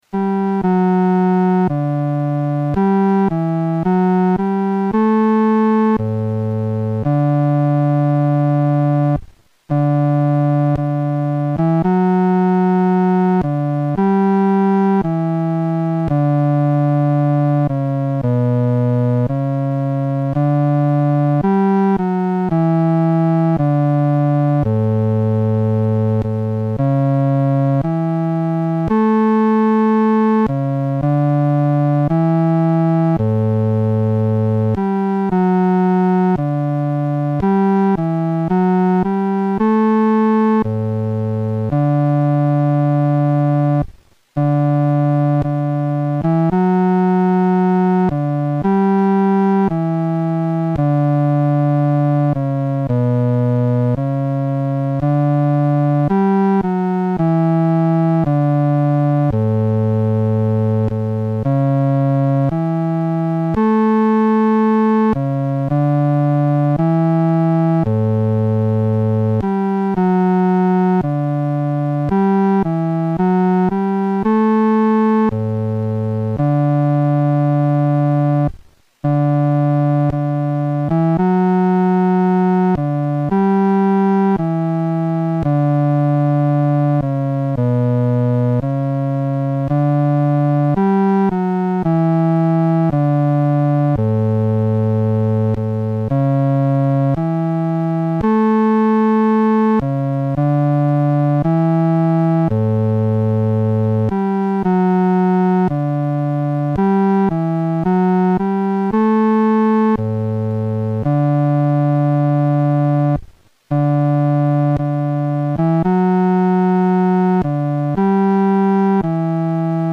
伴奏
男低
本首圣诗由石家庄圣诗班录制